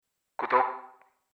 알림음 8_Radio_구독